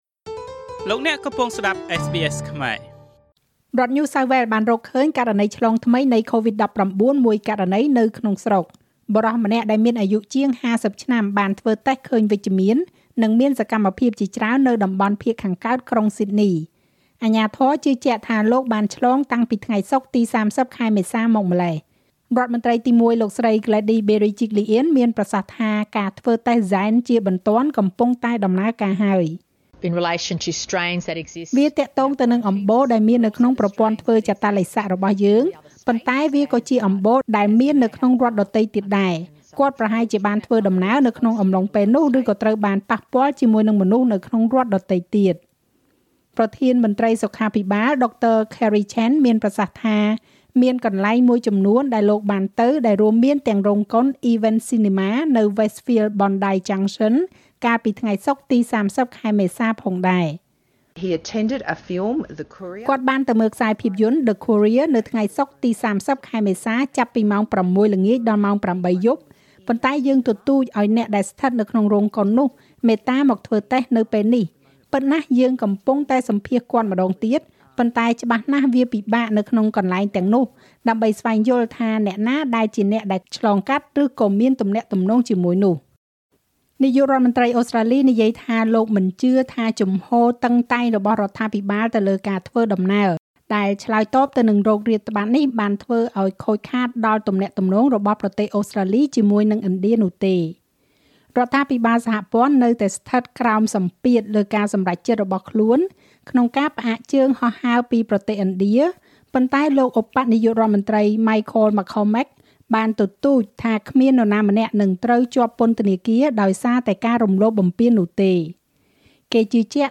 នាទីព័ត៌មានរបស់SBSខ្មែរ សម្រាប់ថ្ងៃពុធ ទី៥ ខែឧសភា ឆ្នាំ២០២១